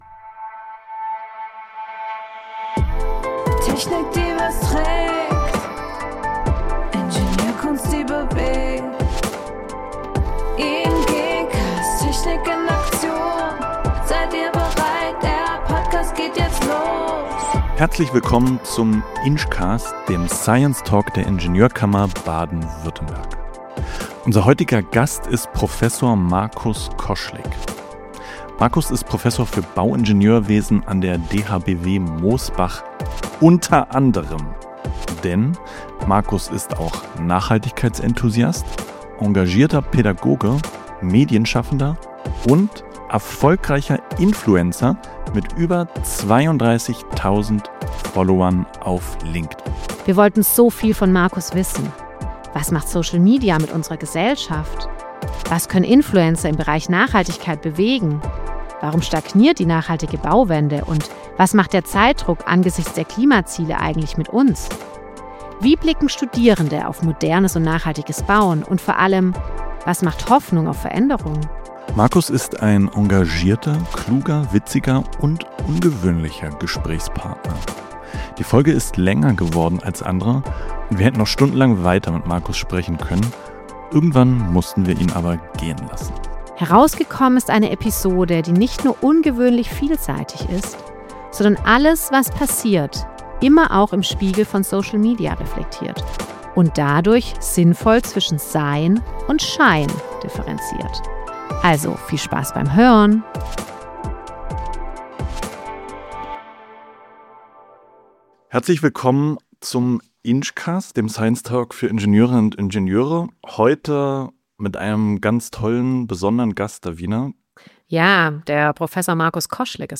Beschreibung vor 2 Wochen Herzlich willkommen zum INGcast – dem Science-Talk der Ingenieurkammer Baden-Württemberg.